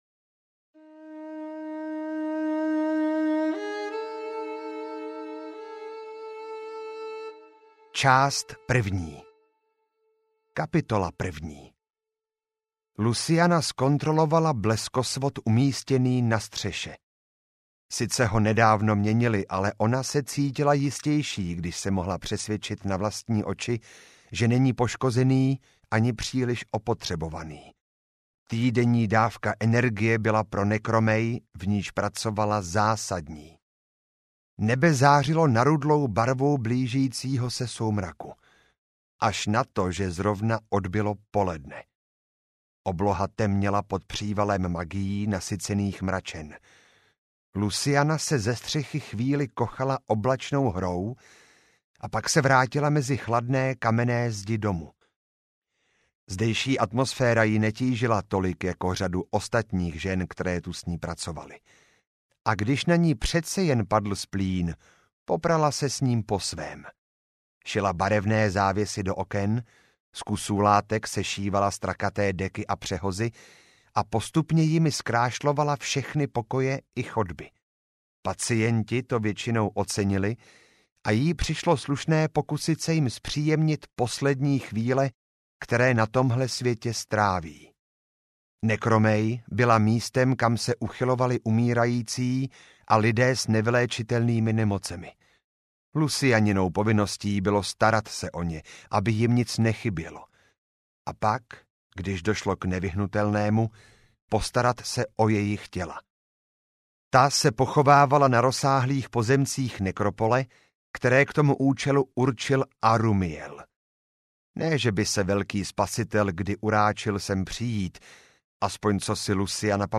Před bouří audiokniha
Ukázka z knihy